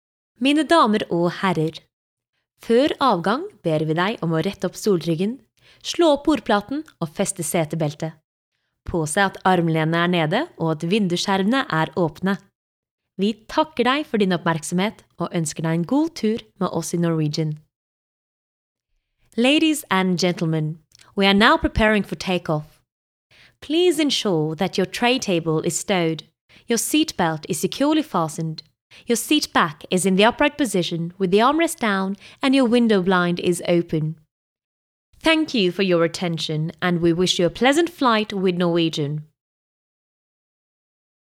Norwegian. Actress. Fresh, emphatic, engaging, dynamic | Voice Shop
English and Norwegian Commercial compilation